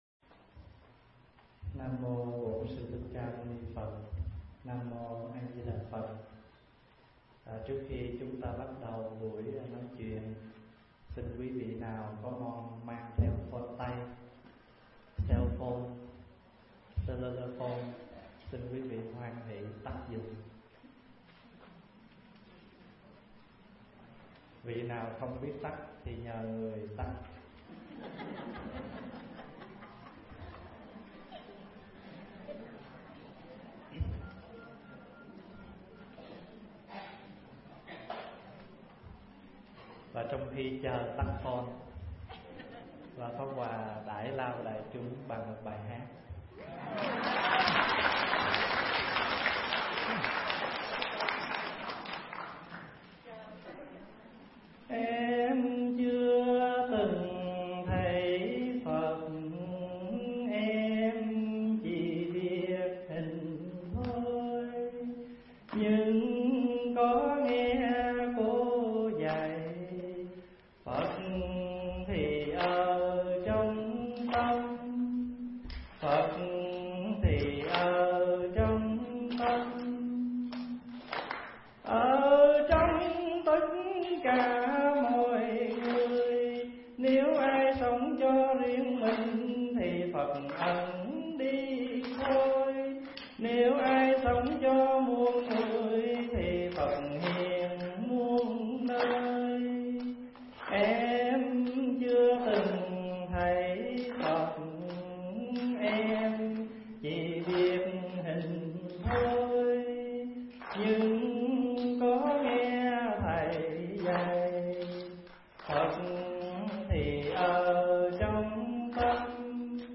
Tải mp3 Thuyết Pháp Sám Khể Thủ 2 – Đại Đức Thích Pháp Hòa thuyết giảng tại Như Lai Thiền Tự, ngày 21 tháng 12 năm 2013